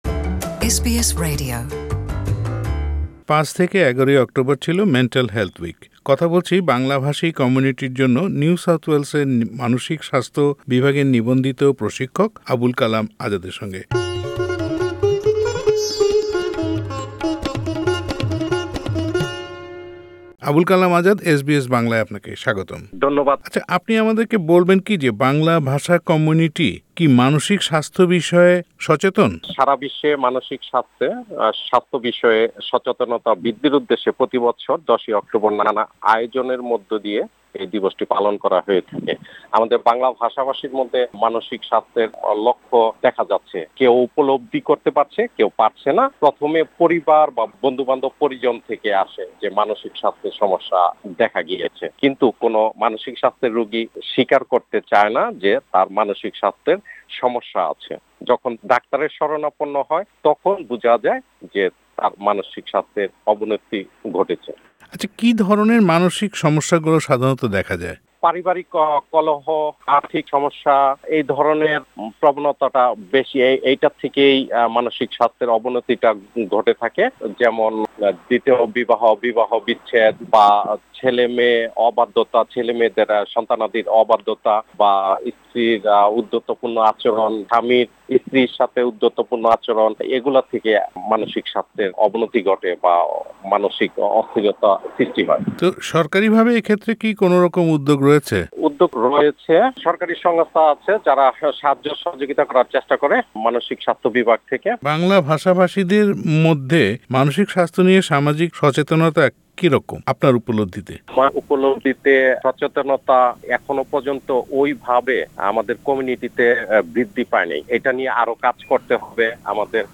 Mental Health : Interview